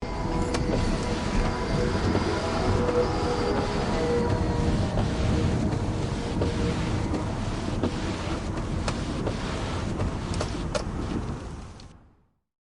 Toyot Hybrid Driving In The Rain
Toyot Hybrid Driving In The Rain is a free nature sound effect available for download in MP3 format.
Toyot HYbrid Driving in the Rain.mp3